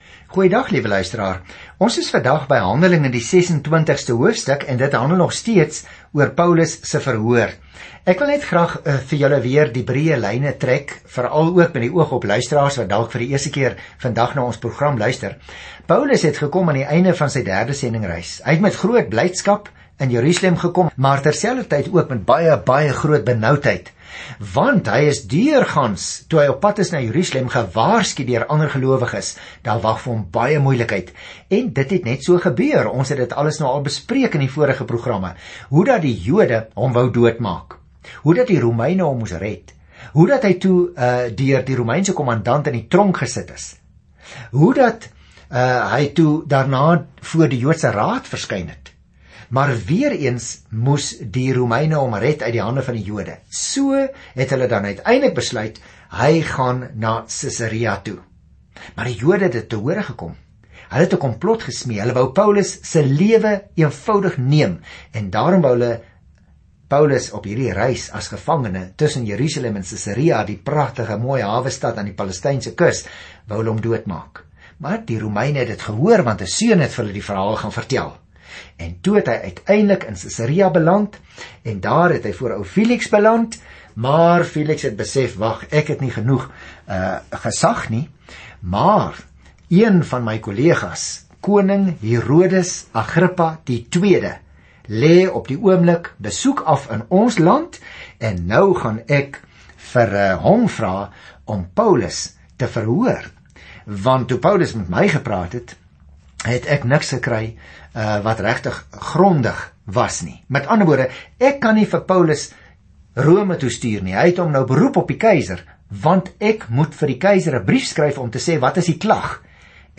Skrif HANDELINGE 26 Dag 33 Begin met hierdie leesplan Dag 35 Aangaande hierdie leesplan Jesus se werk begin in die Evangelies gaan nou voort deur sy Gees, soos die kerk geplant word en groei oor die hele wêreld. Reis daagliks deur Handelinge terwyl jy na die oudiostudie luister en uitgesoekte verse uit God se woord lees.